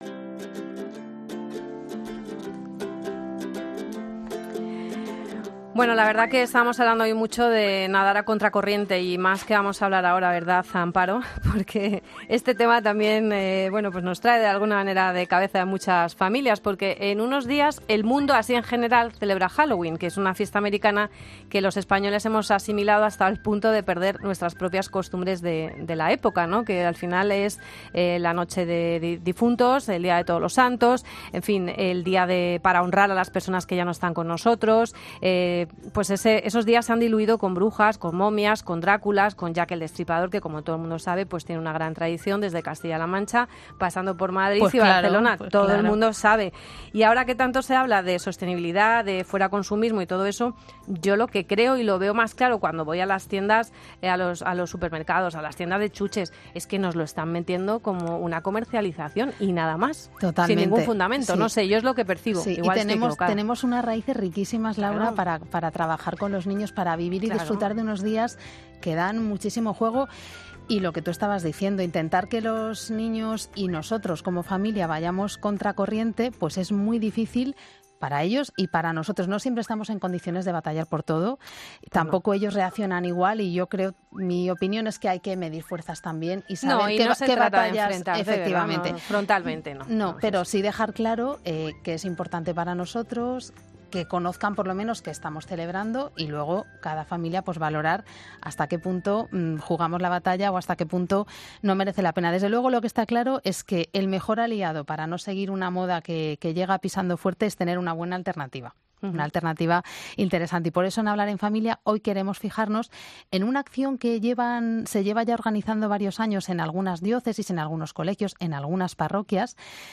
Si quieres ideas de cómo lo hacen no te pierdas la entrevista.